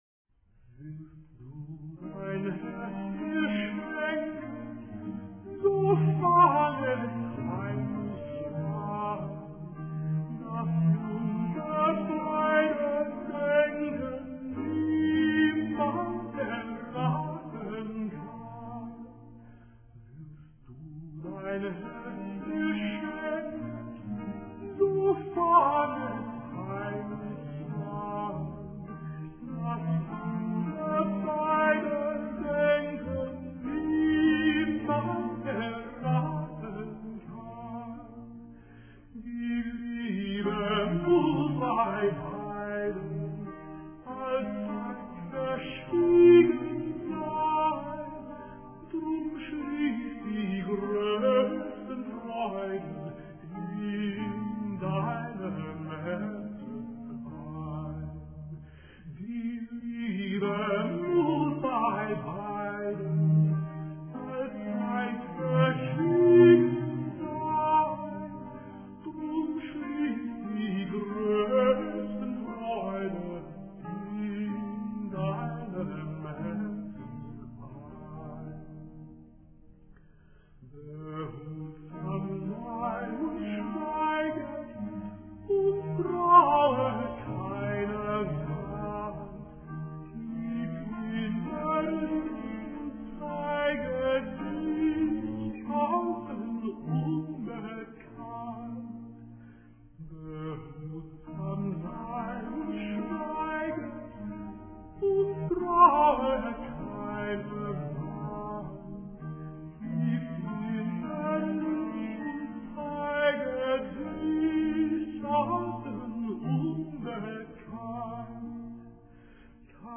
mp3 mono 8kbps